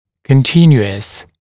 [kən’tɪnjuəs][кэн’тинйуэс]непрерывный, постоянный; цельный (о дуге)